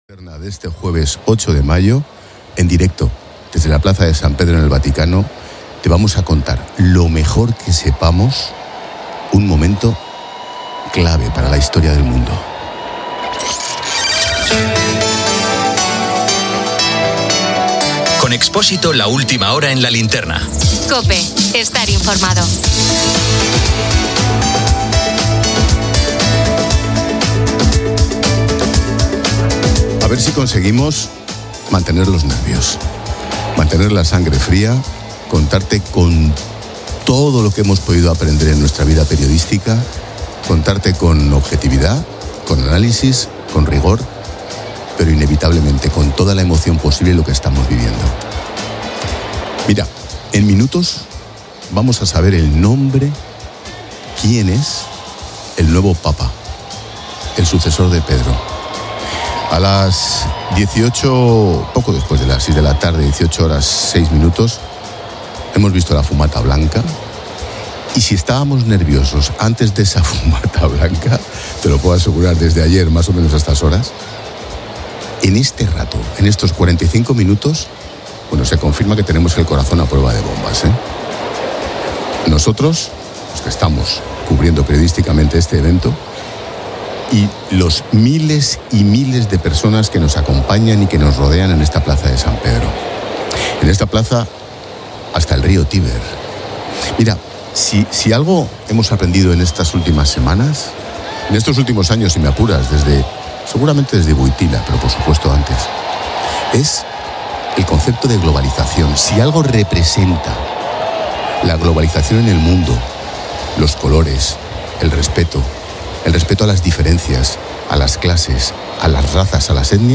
f8c45ae7eaad49948a27785ca0be383b23030af5.mp3 Títol Cadena COPE Emissora Ràdio Popular de Barcelona (COPE Barcelona) Cadena COPE Titularitat Privada estatal Nom programa La linterna Descripció Hora, careta i retransmissió de la proclamació del Sant Pare Lleó XIV, el cardenal Robert Prevost Martínez, des de la Bsílica de Sant Pere a la Ciutat del Vaticà. Primer discurs seu, benedicció urbi et orbi.
Gènere radiofònic Informatiu